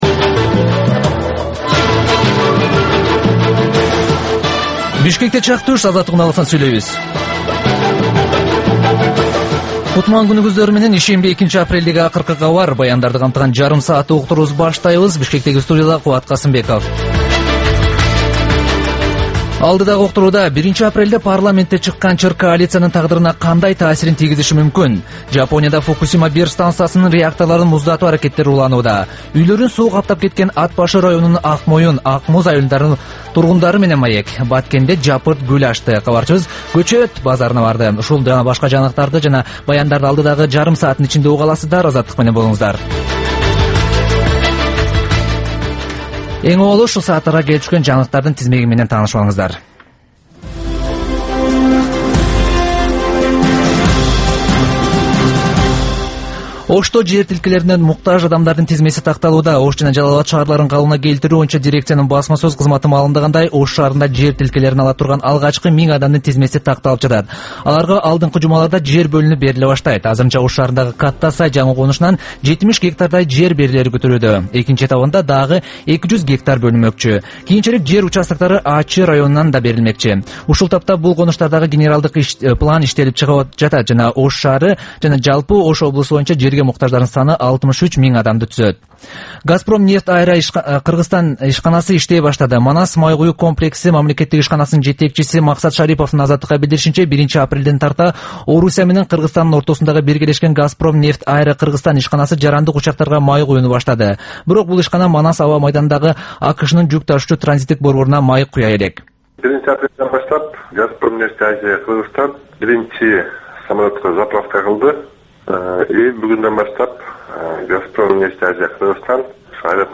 Түшкү саат 1деги кабарлар